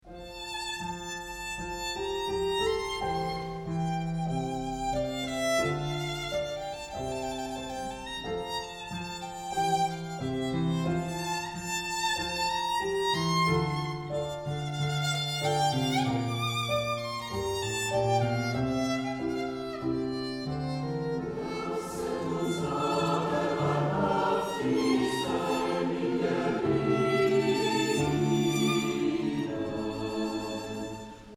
Violine, Chor, Klavier